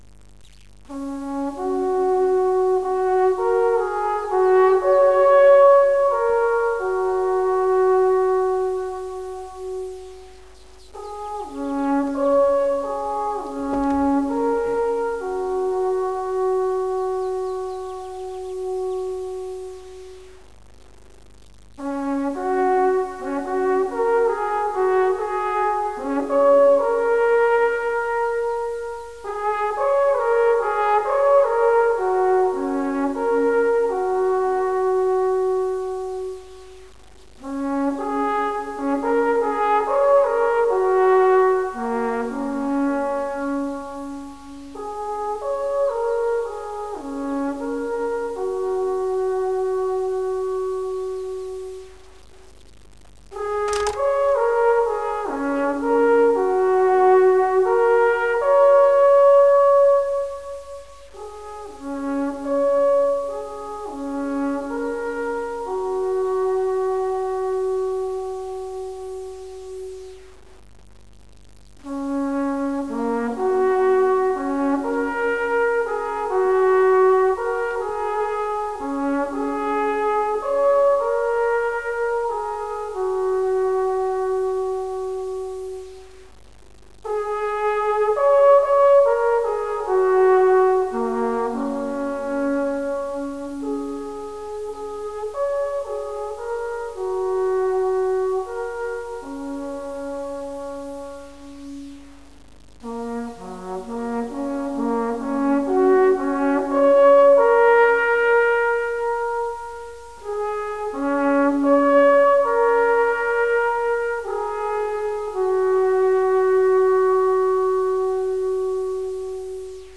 Das Alphorn  -  hier klingt es: